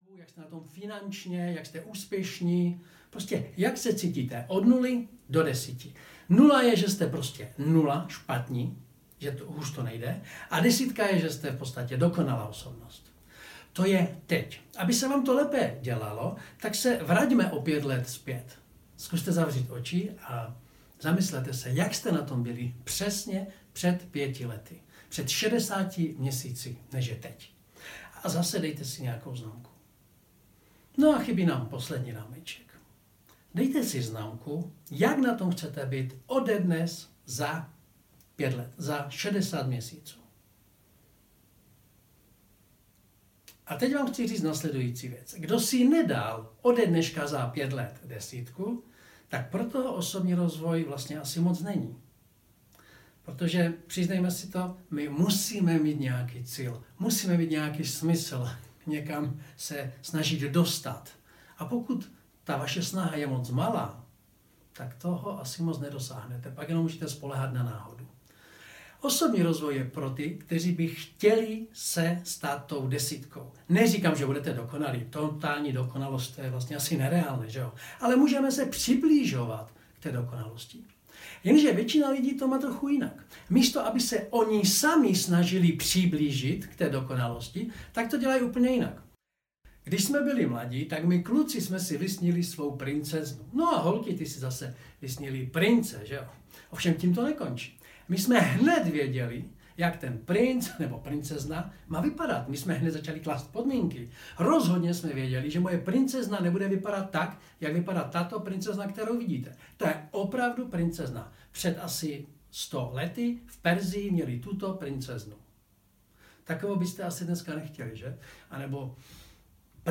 Debordelizace hlavy audiokniha
Ukázka z knihy